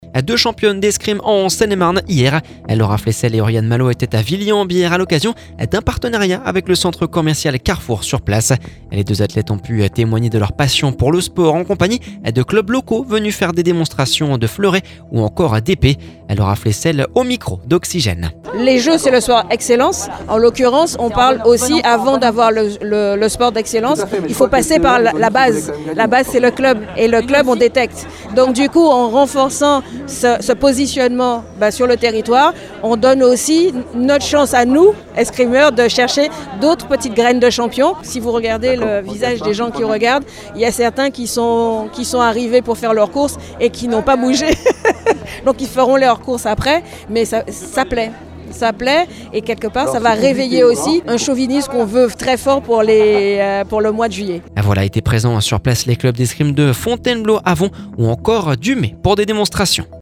Laura Flessel au micro d’Oxygène…